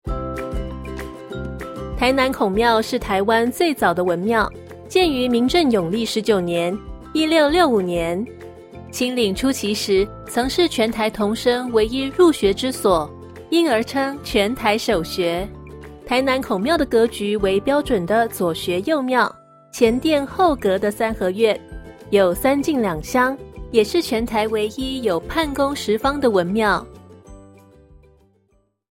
中文語音解說